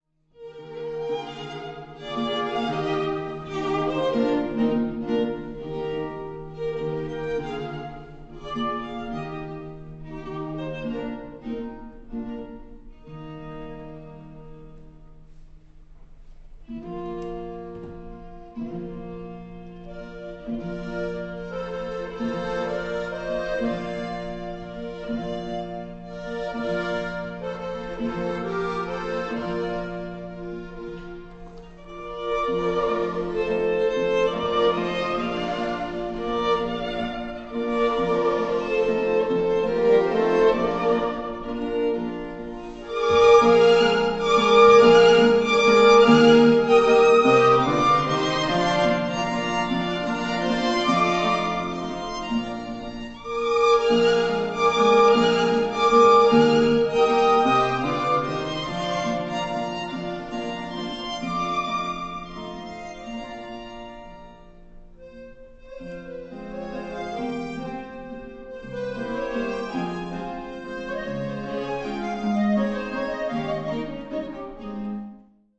** Quartett mit Knopfharmonika
Aufgenommen live am 13.5.2007,